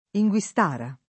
inguistara [ i jgU i S t # ra ]